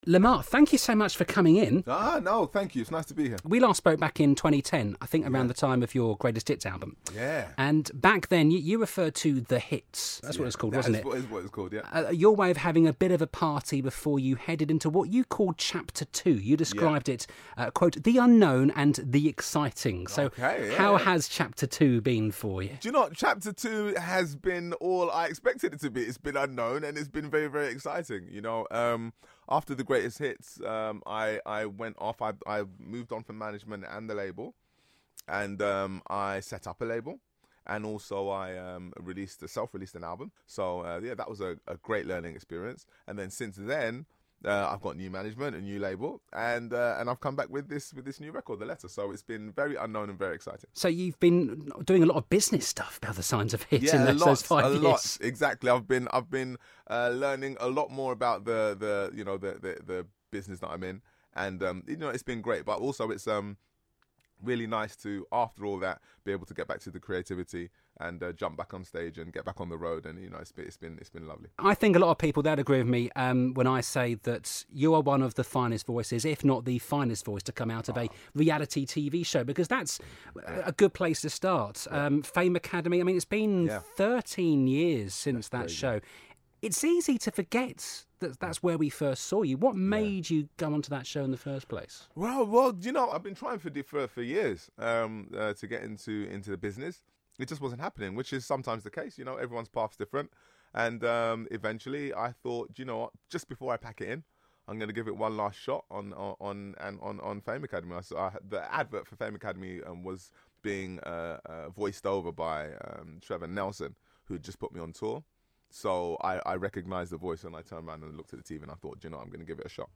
plus he performs live in the studio.